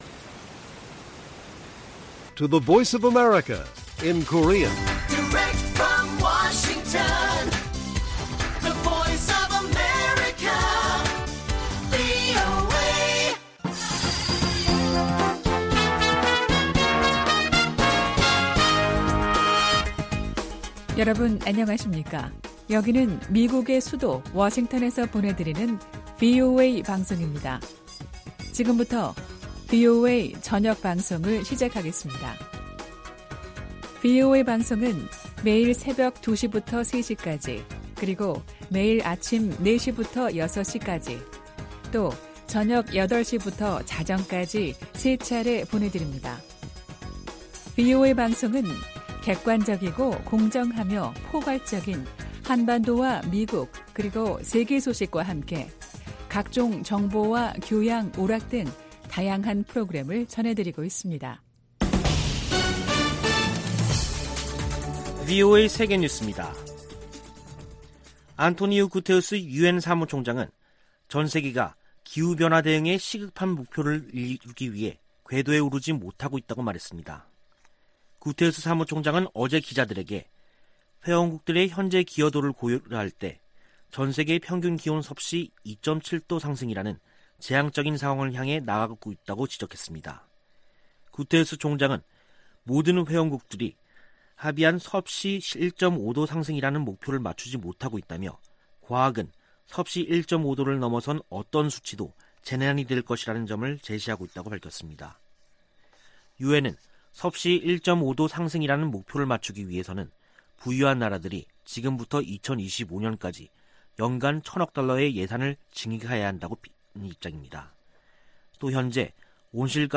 VOA 한국어 간판 뉴스 프로그램 '뉴스 투데이', 2021년 9월 21일 1부 방송입니다. 북한이 우라늄 농축 등 핵 프로그램에 전력을 기울이고 있다고 국제원자력기구 사무총장이 밝혔습니다. 미 국무부는 북한의 최근 핵 관련 움직임과 탄도미사일 발사가 유엔 안보리 결의 위반이며 한국과 일본 등에 위협이라고 지적했습니다. 미 국방부는 한국보다 더 강력한 동맹국은 없다며, 한반도 위협에 군사적 대비태세를 갖추고 있다고 강조했습니다.